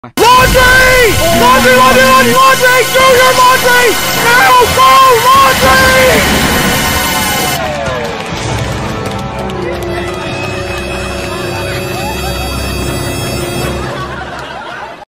(WARNING THIS IS REALLY LOUD) File:DougDougLaundryLaundry.mp3 DO YOUR LAUNDRY!!!!!
*sounds of a feedback loop getting exponentially louder*" -Drake This page contains an audio file that is either very loud or has high frequencies.